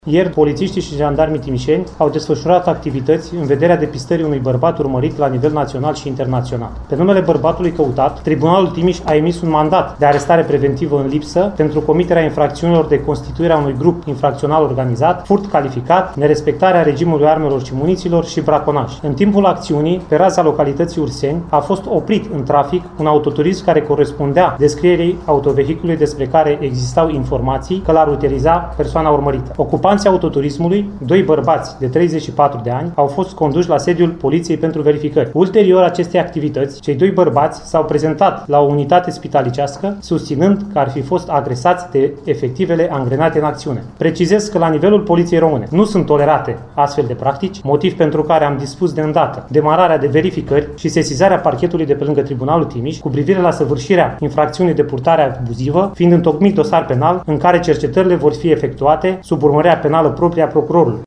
Șeful Poliţiei Timiș, Alin Petecel, spune că Parchetul de pe lângă Tribunalul Timiș a deschis o anchetă în acest caz.